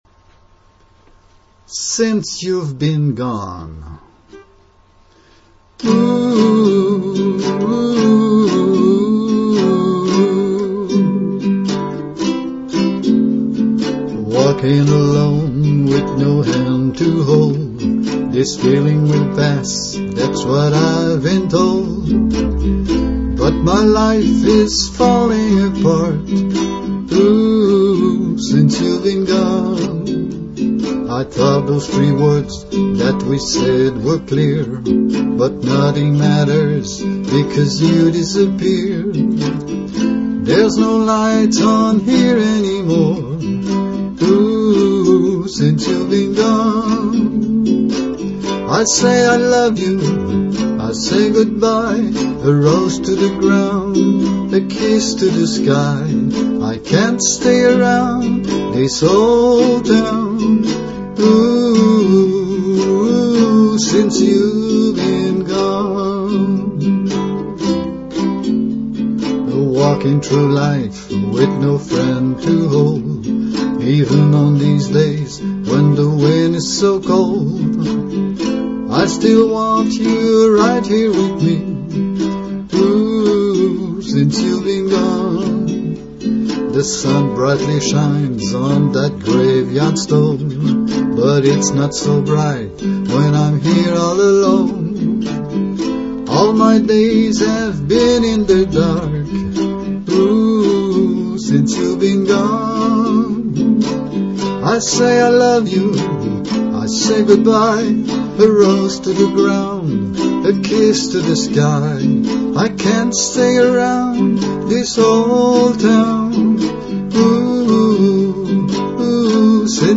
My voice + ukulele.